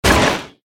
KART_Hitting_Wood_Fence.ogg